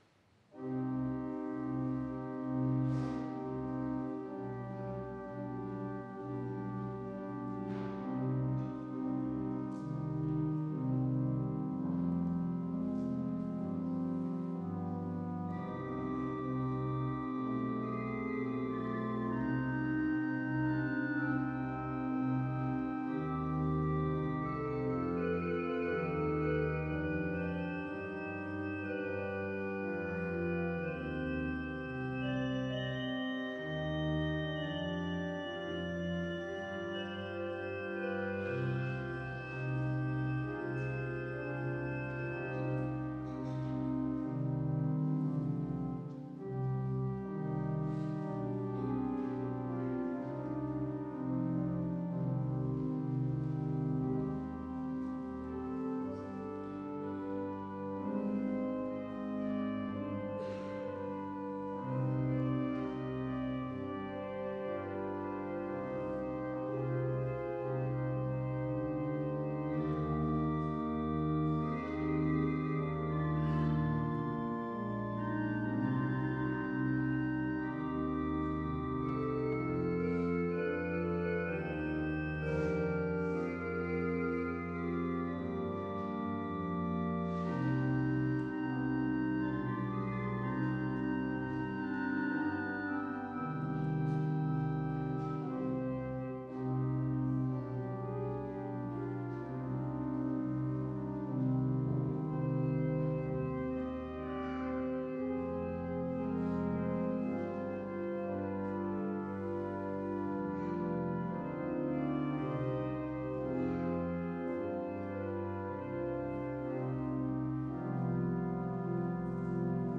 Audiomitschnitt unseres Gottesdienstes vom Sonntag Lätare 2024